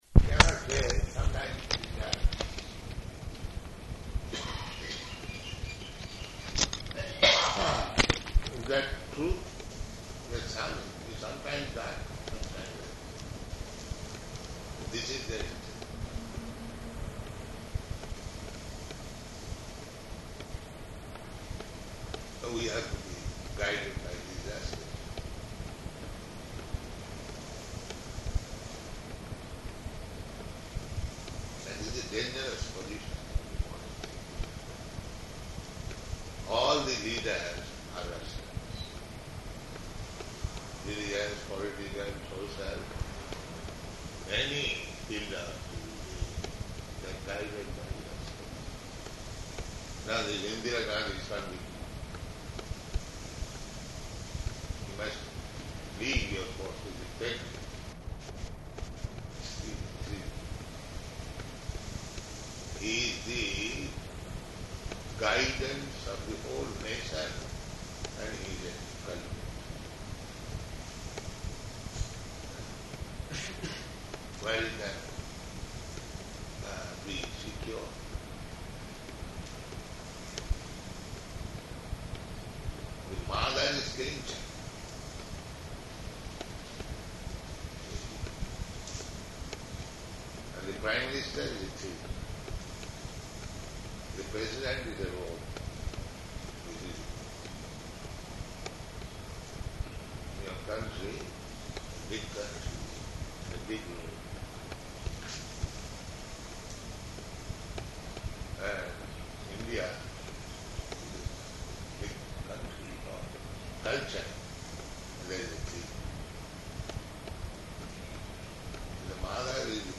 Room Conversation
Room Conversation --:-- --:-- Type: Conversation Dated: May 13th 1976 Location: Honolulu Audio file: 760513R2.HON.mp3 Prabhupāda: ...cannot say sometimes it is dark.